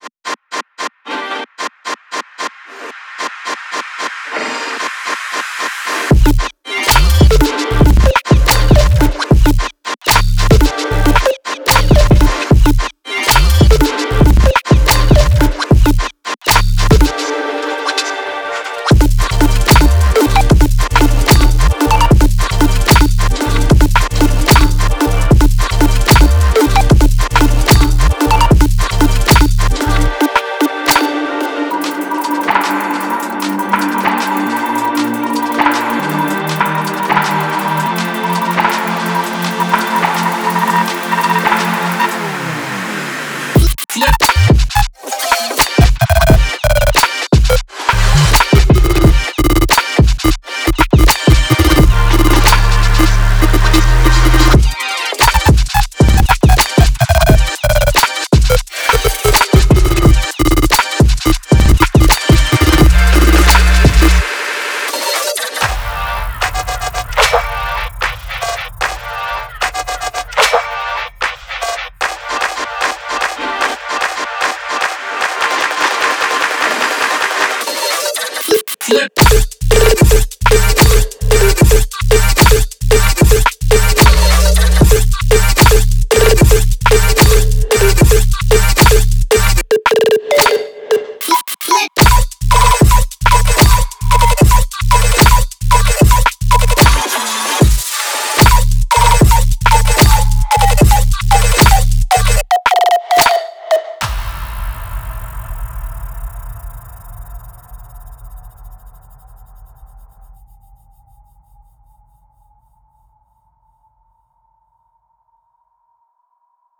gm sine waves moving through 4 genres.